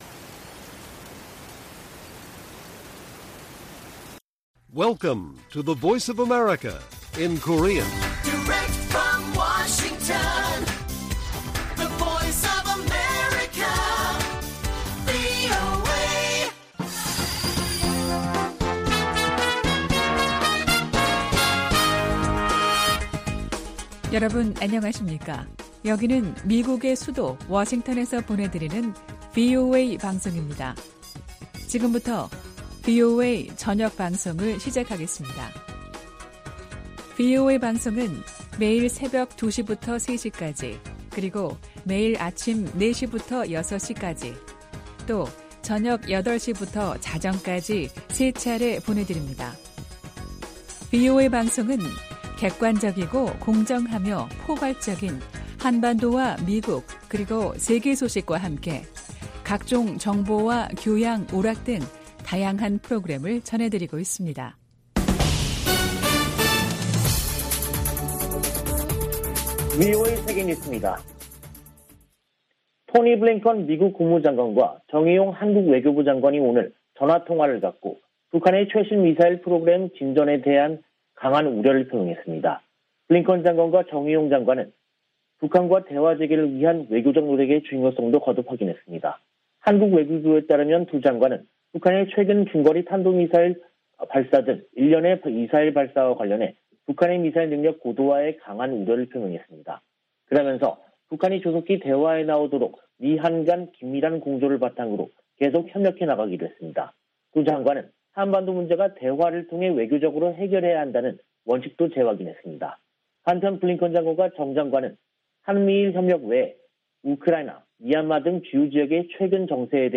VOA 한국어 간판 뉴스 프로그램 '뉴스 투데이', 2022년 2월 3일 1부 방송입니다. 미한 연합훈련 연기 가능성과 관련해 한반도 준비태세를 진지하게 받아들인다고 미 국방부가 밝혔습니다. 미한 외교장관들은 북한의 미사일 능력 고도화에 우려하며, 한반도 문제는 외교적으로 해결해야 한다는 원칙을 재확인했습니다. 오는 6일 개최하는 북한 최고인민회의에 김정은 국무위원장이 등장할지, 어떤 대외 메시지를 발신할지 관심이 모이고 있습니다.